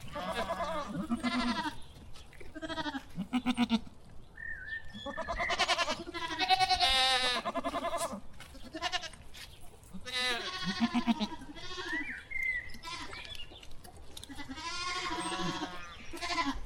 Descarga de Sonidos mp3 Gratis: granja 1.
farm-effects.mp3